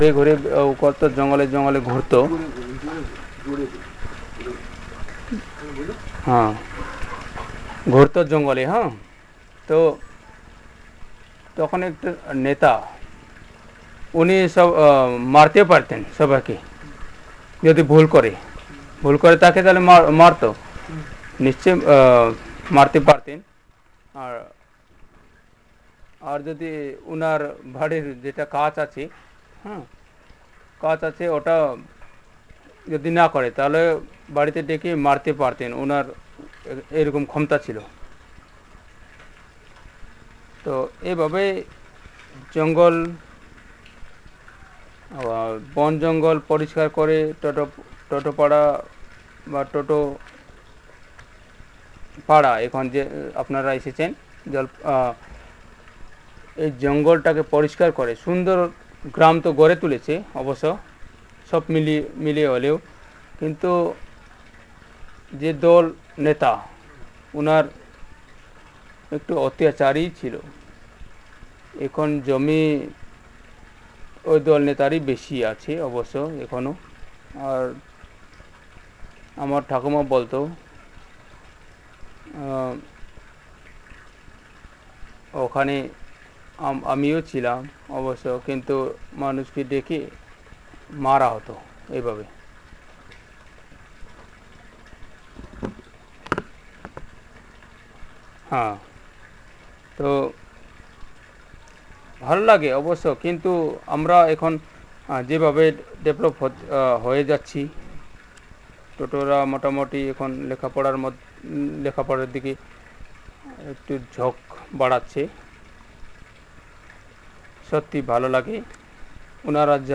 History and social status of the Toto community and performance of some Toto folksongs